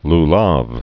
(l-läv, llôv) Judaism